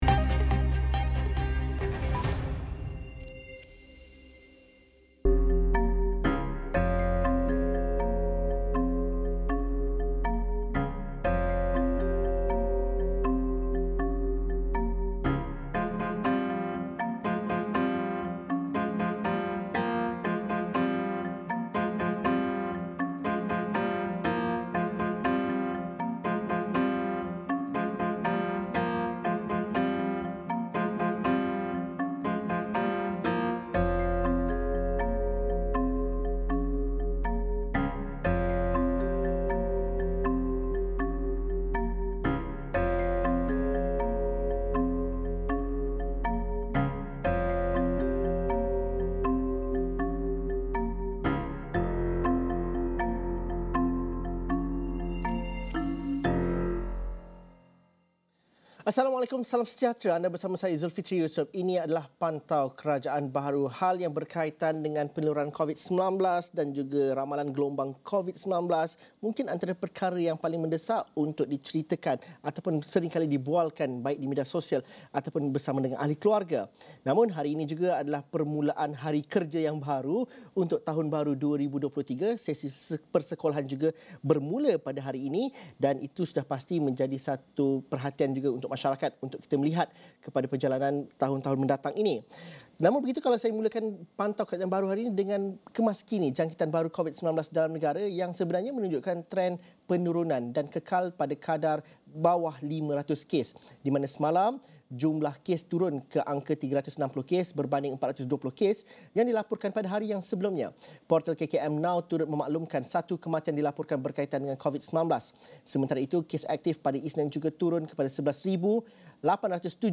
Wajarkah pelancong dari China disekat kerana bimbang akan gelombang baharu? Apa persediaan KKM dalam mengekang penularan? Ikuti perbincangan bersama pakar kesihatan awam